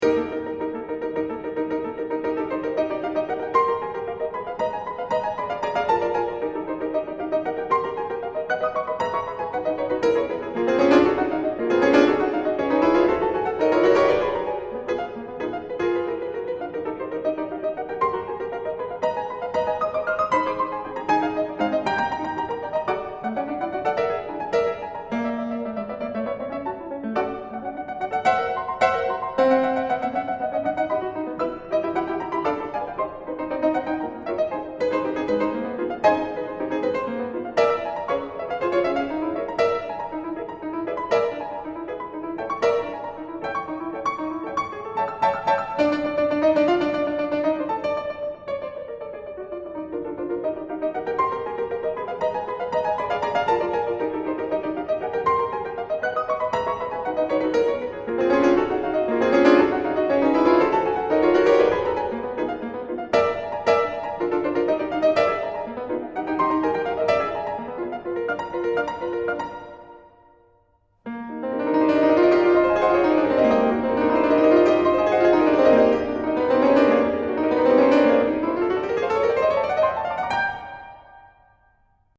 And if you're REALLY ambitious, here's the piece in its entirety, performed on the piano (pianist: Olli Mustonen):